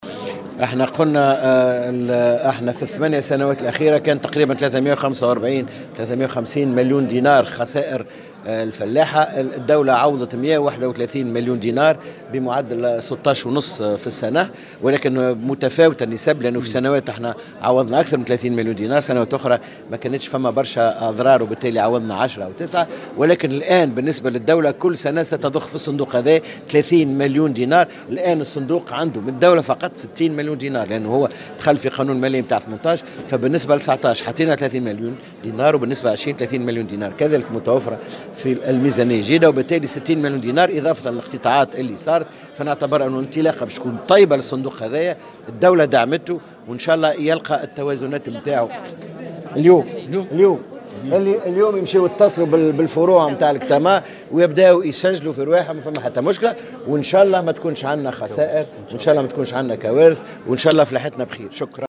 أكد وزير الفلاحة سمير بالطيب في تصريح لمراسل الجوهرة "اف ام" اليوم الإثنين أن القيمة الجملية للأضرار الناجمة عن الجوائح الطبيعية، خاصة منها غير المؤمنة خلال السنوات الثماني الأخيرة بلغت ما يقدر ب345مليون دينار.